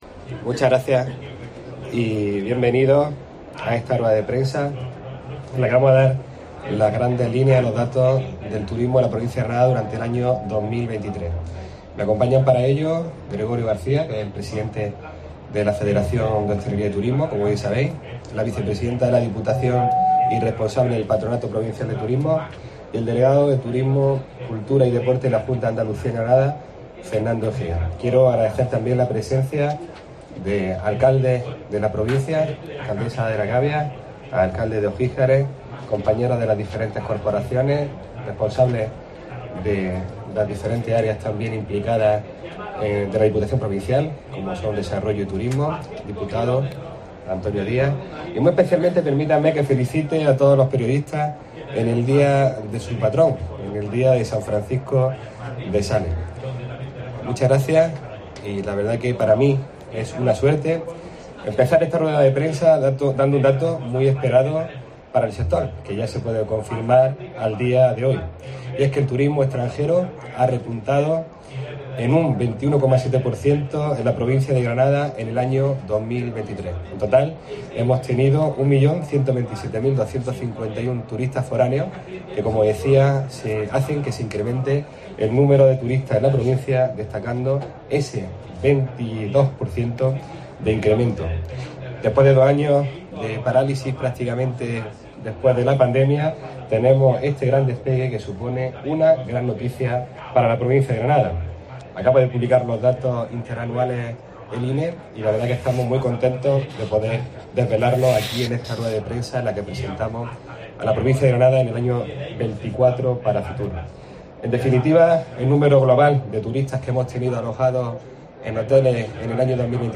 Francis Rodríguez, Presidente de la Diputación
Así se ha pronunciado el presidente provincial en la comparecencia ante los medios en los que ha dado a conocer los datos del mes de diciembre publicados por el Instituto Nacional de Estadística, lo que permite analizar todo el ejercicio en su conjunto.